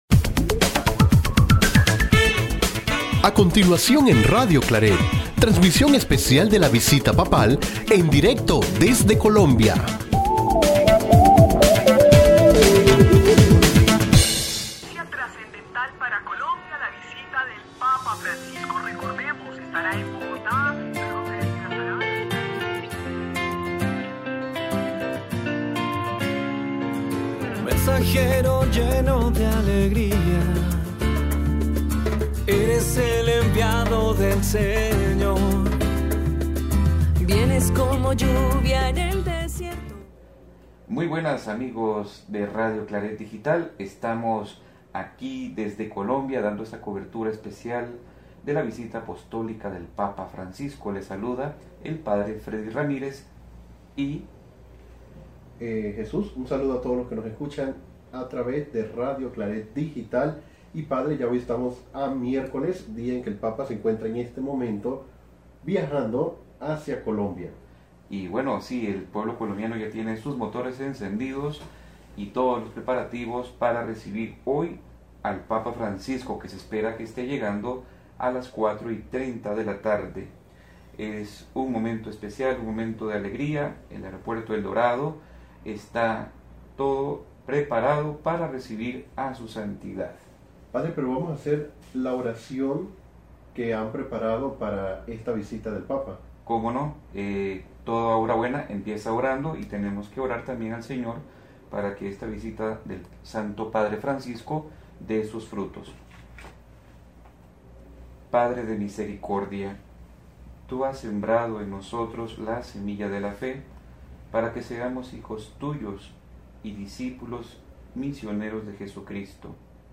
Nuestro segundo programa de la Cobertura de la Visita Apostólica de Francisco desde Medellín.
programa-2-desde-MEDELLIN.mp3